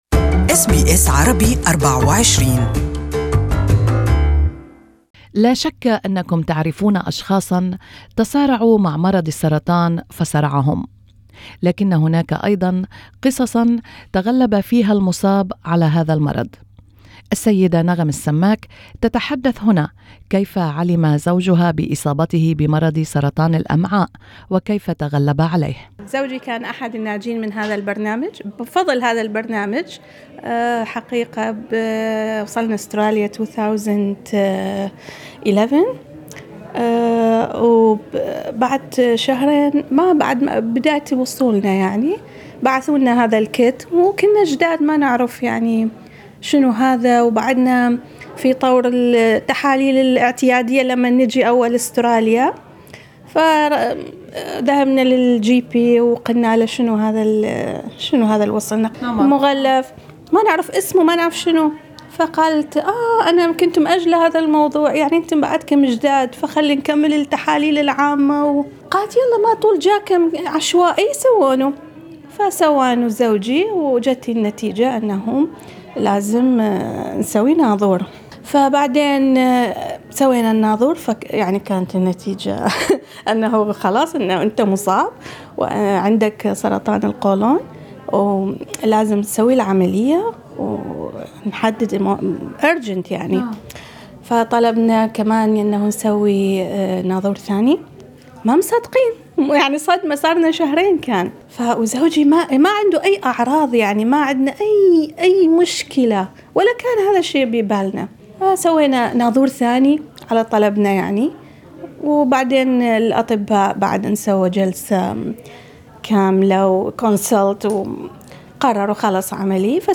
استمعوا إلى اللقائين تحت الشريط الصوتي.